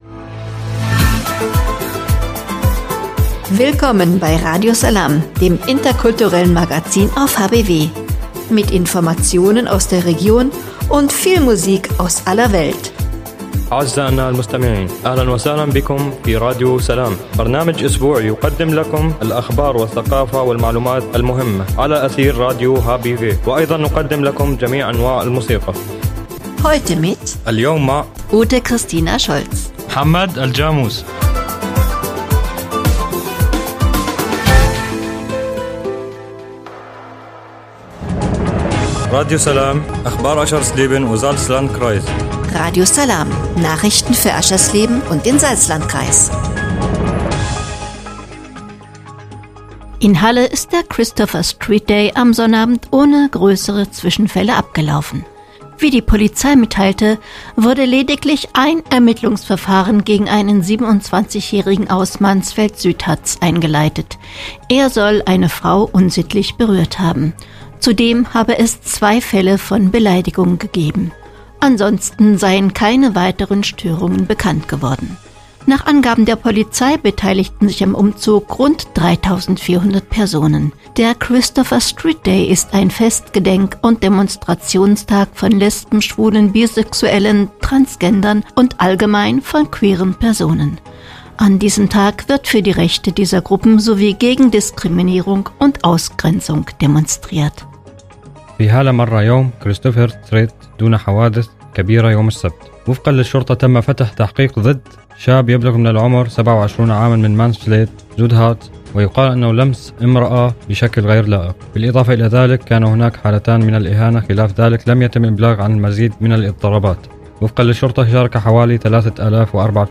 „Radio Salām“ heißt das interkulturelle Magazin auf radio hbw.